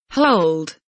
hold kelimesinin anlamı, resimli anlatımı ve sesli okunuşu